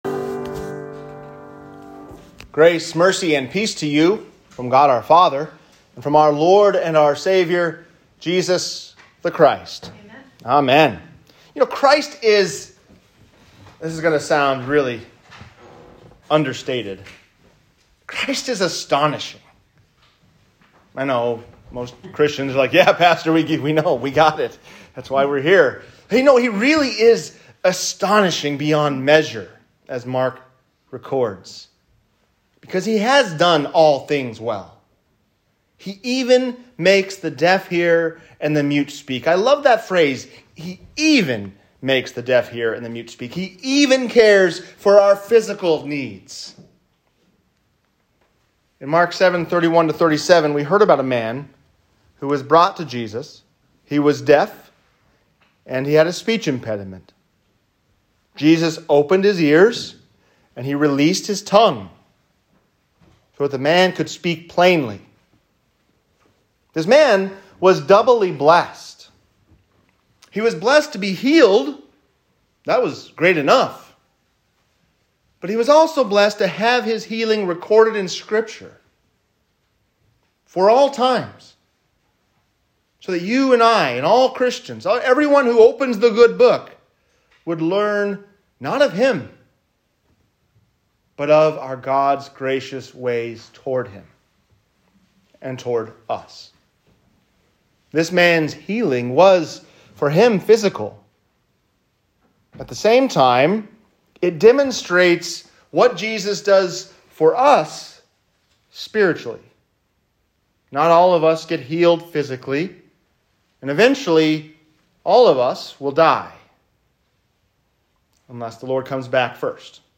| Sermon – St. Mark Lutheran Church
Healed to Hear. Healed to Speak. | Sermon